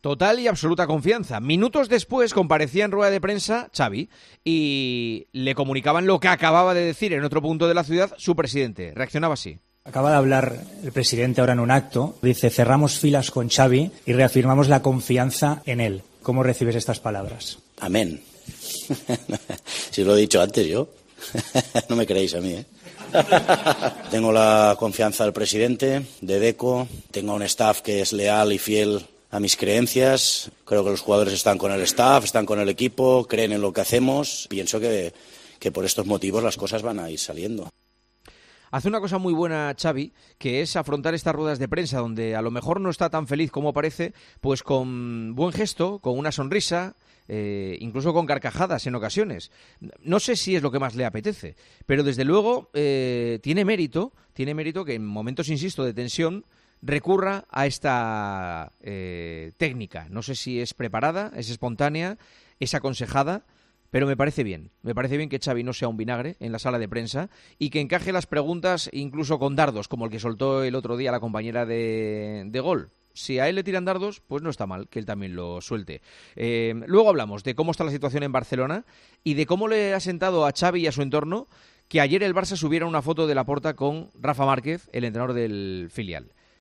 ESCUCHA LA REFLEXIÓN DE JUANMA CASTAÑO SOBRE LA ACTITUD DE XAVI ANTE LA PRENSA EN LAS ÚLTIMAS COMPARECENCIAS